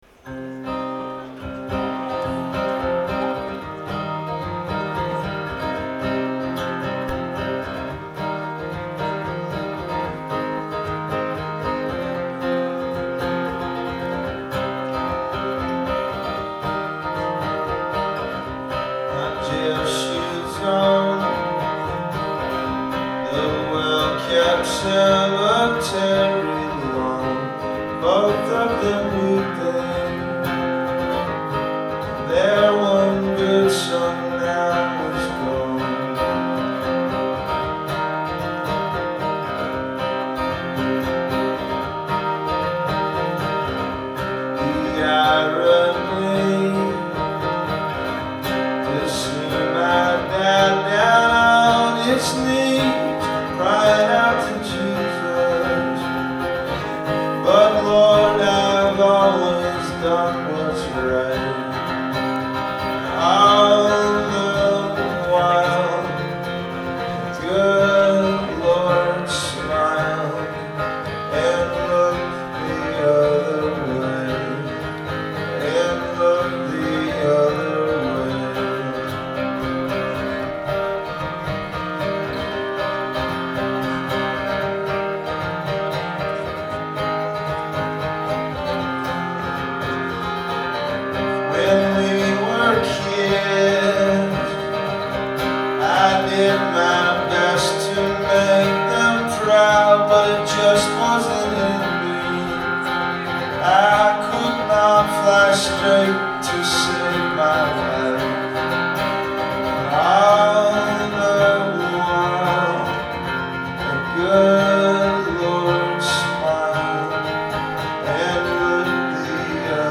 Live at the Somerville Theatre
in Somerville, Massachusetts